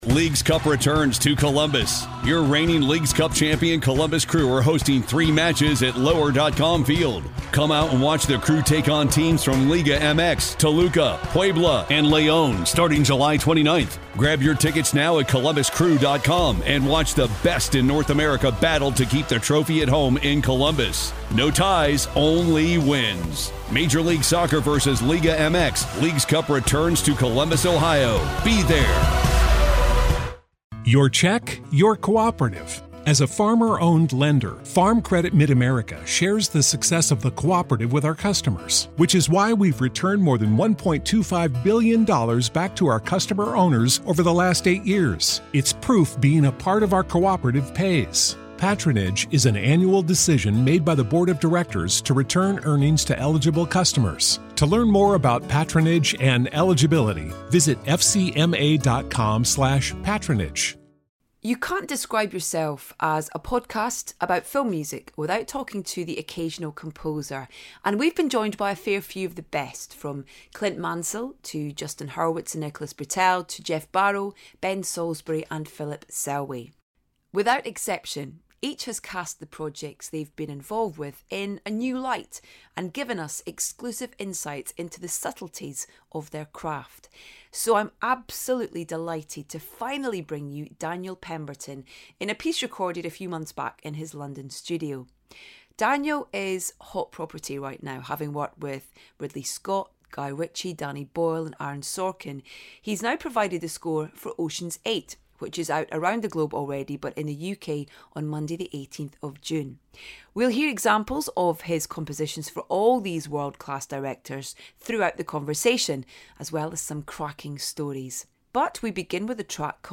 So we're delighted to finally bring you Daniel Pemberton, in a piece recorded a few months back in his London studio.
We'll hear examples of his compositions for all these world-class directors throughout the conversation, as well as some cracking stories.